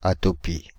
Ääntäminen
Ääntäminen France (Île-de-France): IPA: /a.tɔ.pi/ Haettu sana löytyi näillä lähdekielillä: ranska Käännös Konteksti Substantiivit 1.